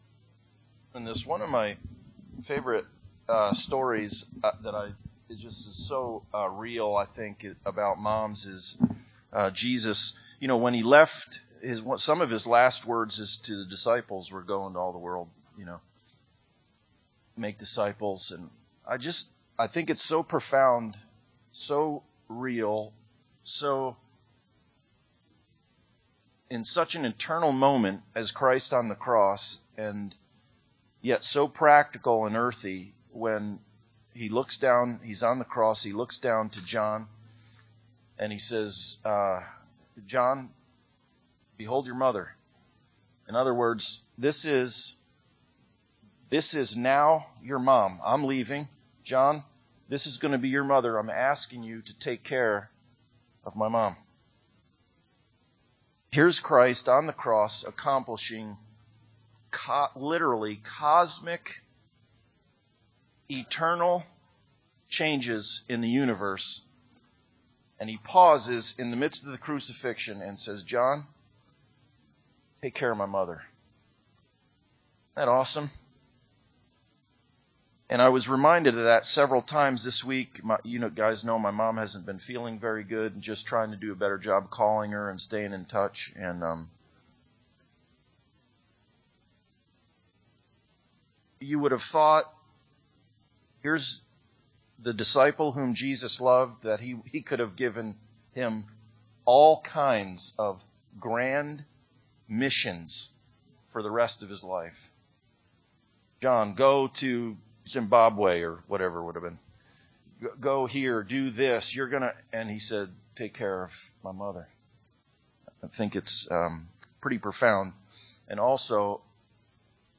Passage: Romans 8:1-30 Service Type: Sunday Service